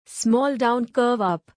small-down-curve-up.mp3